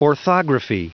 Prononciation du mot orthography en anglais (fichier audio)
Prononciation du mot : orthography